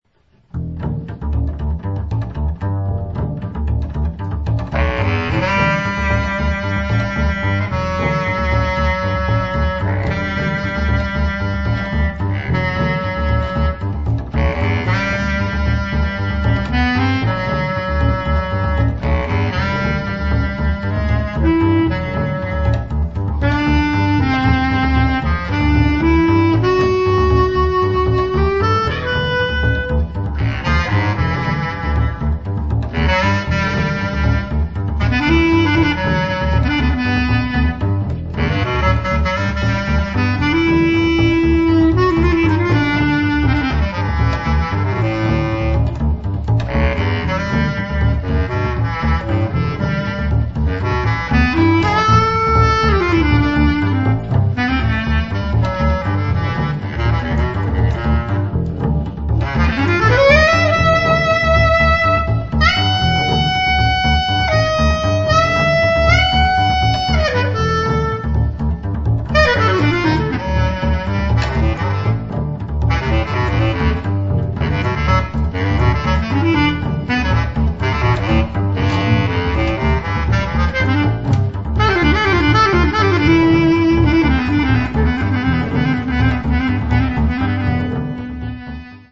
out there instrumental jazz duo